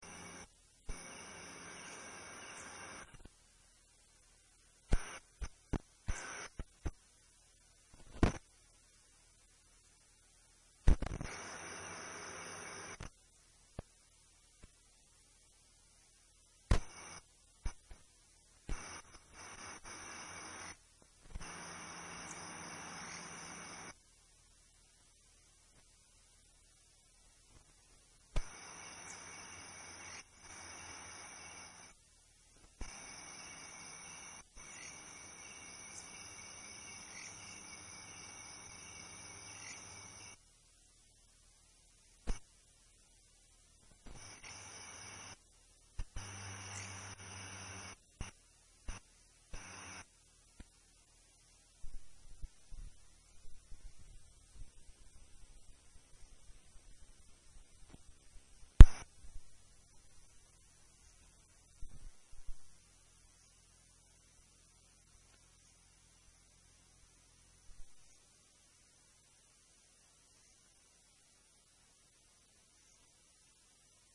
描述：日落草甸，昆虫与鸟类鸣叫。
Tag: 森林 昆虫 实地录音 舒缓 环境 鸟类 自然 夏季 草地 草原